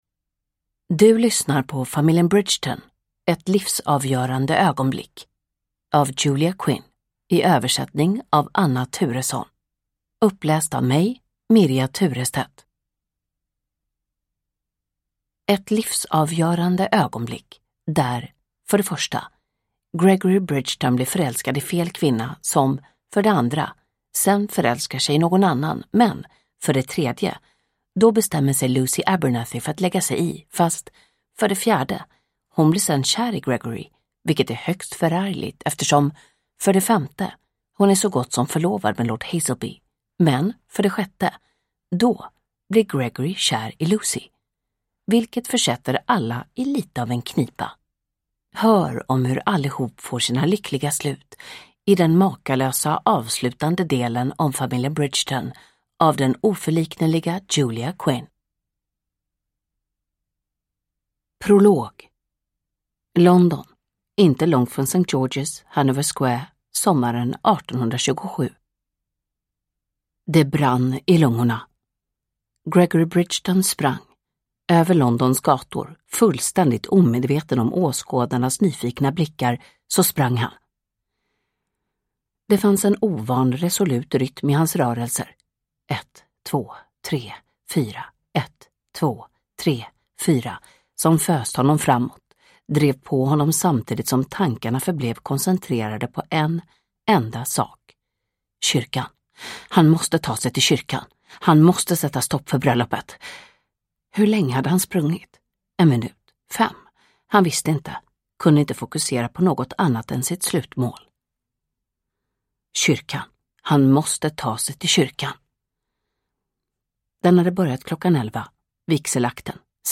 Inferno – Ljudbok – Laddas ner
Uppläsare: Jonas Malmsjö